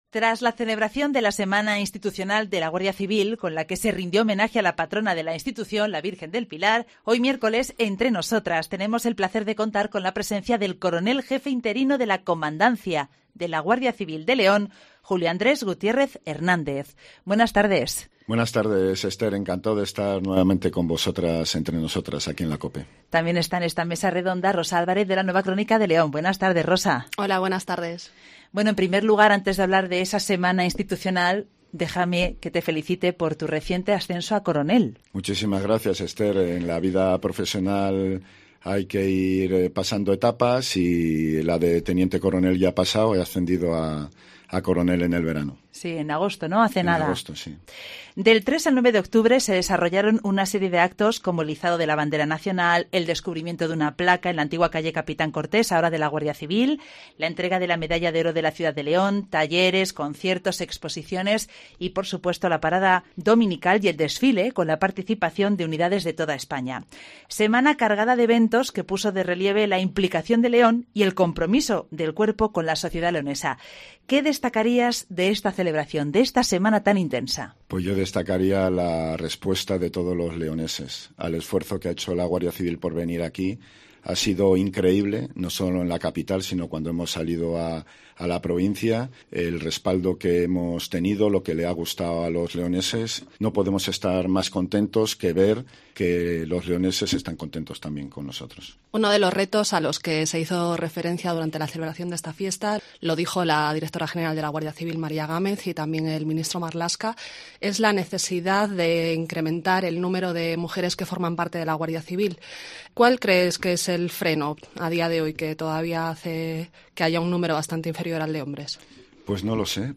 Tras la celebración de la semana institucional de la Guardia Civil con la que se rindió homenaje a la patrona de la institución, la Virgen del Pilar, hoy miércoles “Entre Nosotras”, tenemos el placer de contar con la presencia en Cope León del Coronel Jefe interino de la Comandancia de la Guardia Civil de León, Julio Andrés Gutiérrez Hernández.